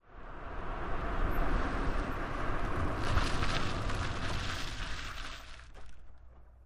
Tires on Dirt
Car Tires Gritty; Skid Stops, On Board Perspective 4x